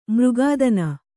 ♪ mřgādana